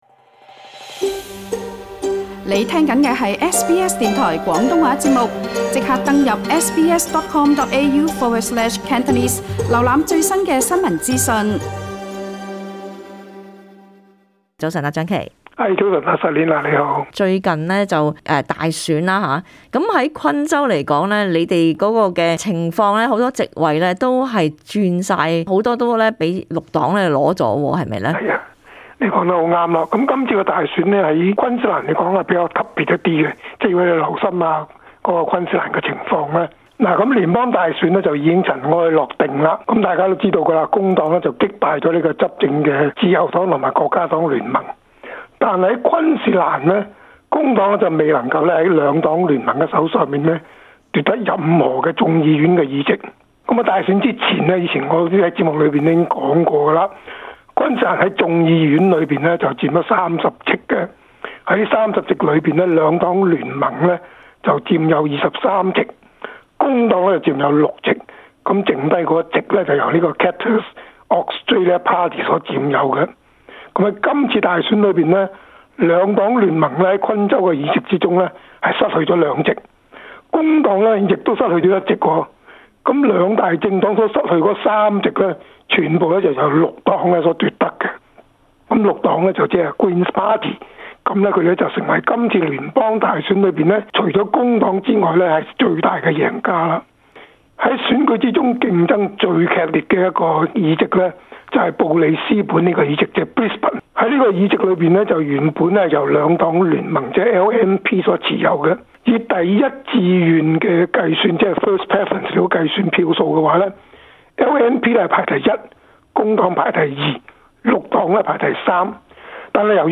布里斯本通訊